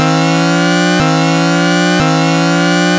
そこで、基本となる300Hzのノコギリ波に、異なる周波数のノコギリ波をいくつも足してやる。
こんな音になった。まぁ、元のシンプルすぎる音よりは、だいぶ厚みが出て多少はそれらしくはなった。
まだまだ、コレジャナイ、という音ではあるが。